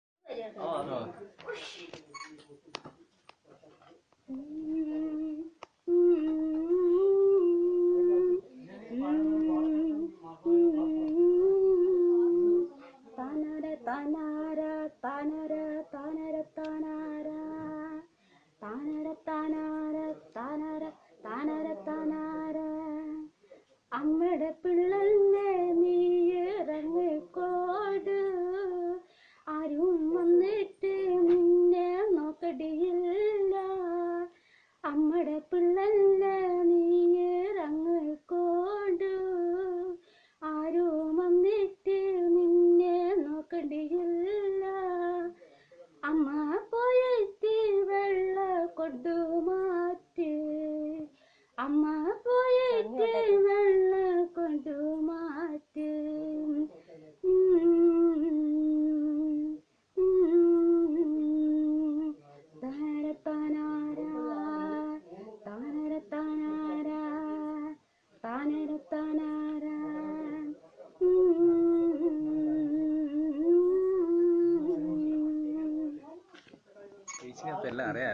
Performance of folk song